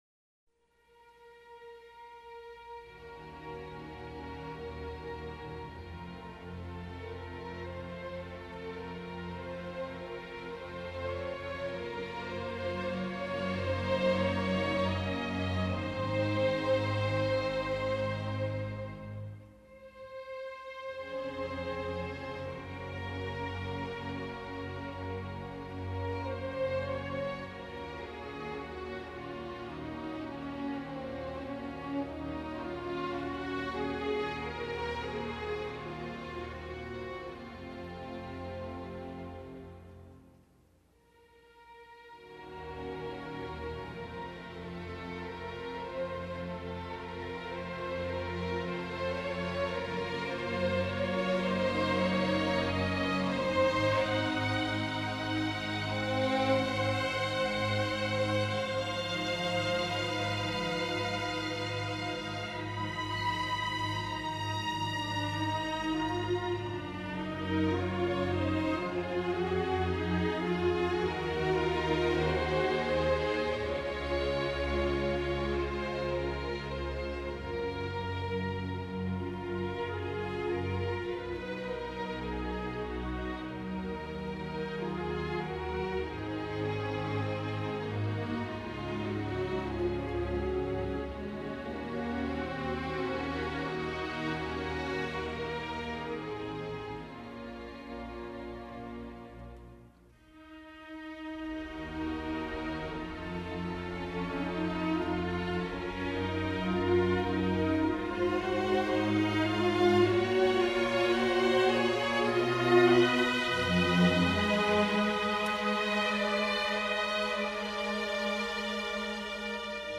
موسیقی بی کلام , کلاسیک